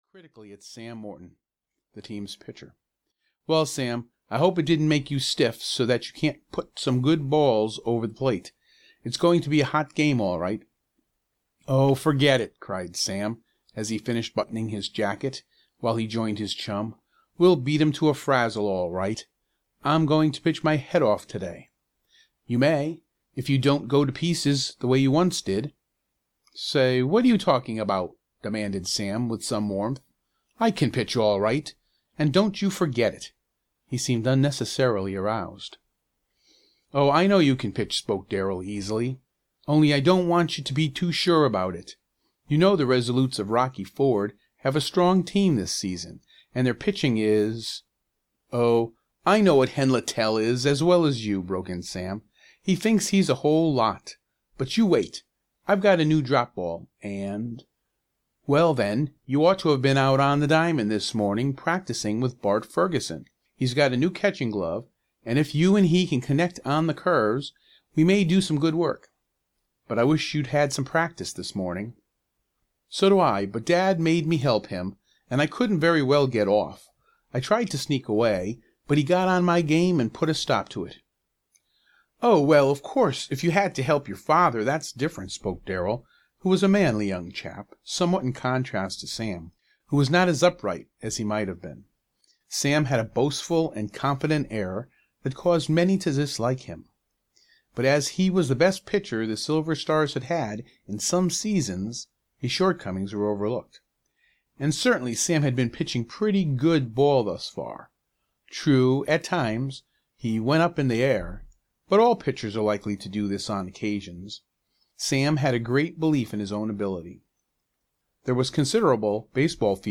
Baseball Joe of the Silver Stars (EN) audiokniha
Ukázka z knihy